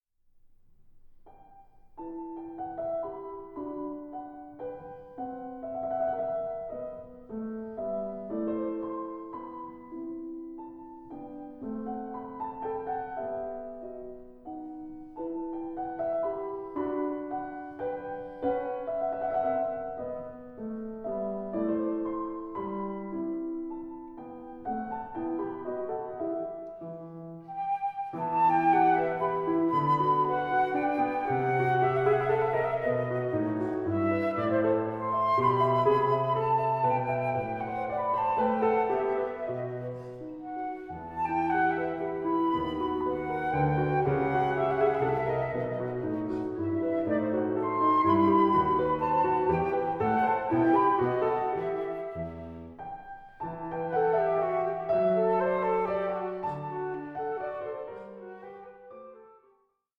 version for flute and piano